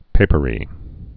(pāpə-rē)